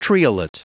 Prononciation du mot triolet en anglais (fichier audio)
Prononciation du mot : triolet